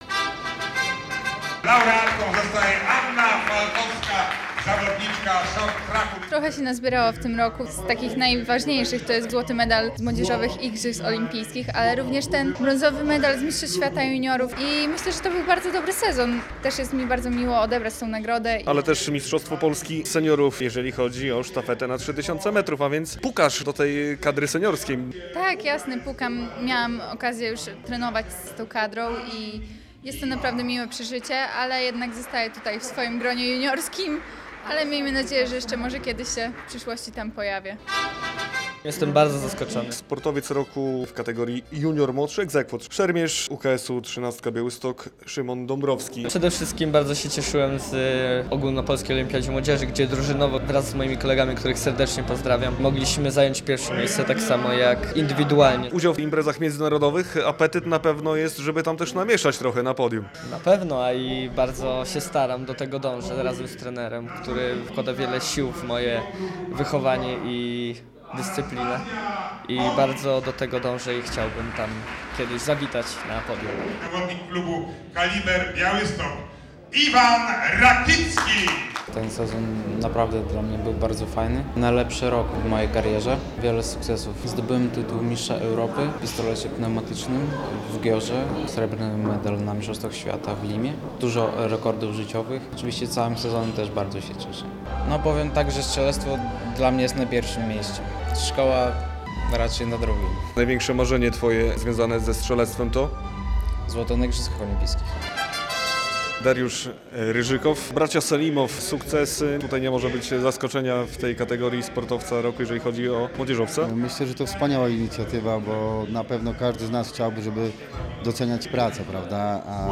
II Podlaska Gala Sportu Młodzieżowego - relacja